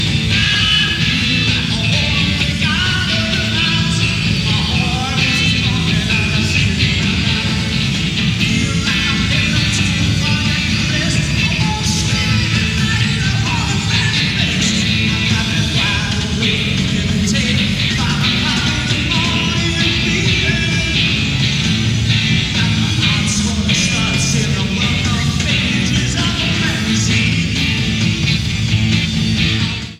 Format/Rating/Source: CD - C- - Audience
Comments: Fair audience recording
Sound Samples (Compression Added):